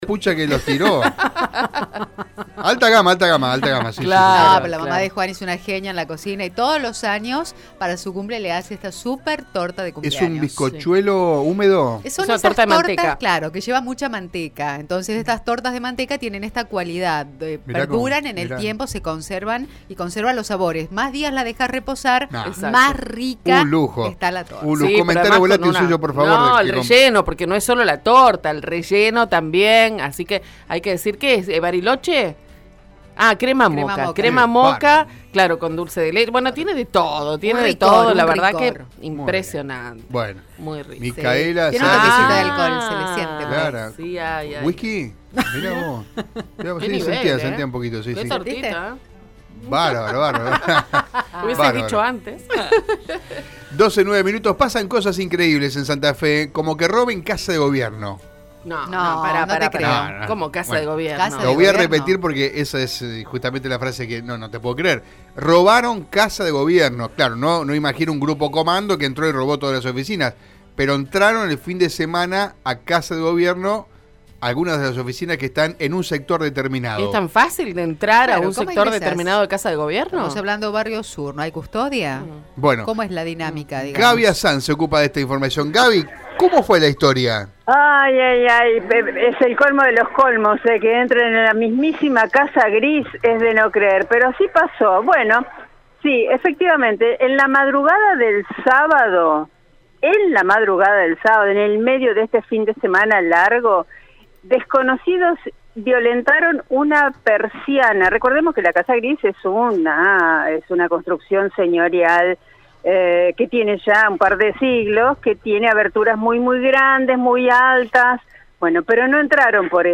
Según el móvil de Radio EME, en horas de la madrugada del sábado, ladrones violentaron una persiana perteneciente a una de las ventanas de la sede de gobierno, del lado de calle San Martín casi intersección con Amenábar de la ciudad de Santa Fe.